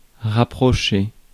Ääntäminen
IPA: [ʁa.pʁɔ.ʃe]